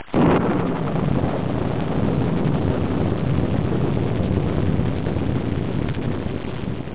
home *** CD-ROM | disk | FTP | other *** search / AMOS PD CD / amospdcd.iso / samples / thunder ( .mp3 ) < prev next > Amiga 8-bit Sampled Voice | 1989-08-27 | 58KB | 1 channel | 8,363 sample rate | 7 seconds
thunder.mp3